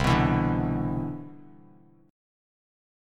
Listen to CM9 strummed